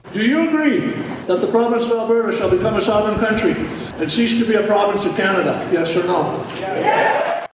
Recently, just north of Edmonton, about a thousand people turned out for a town hall meeting.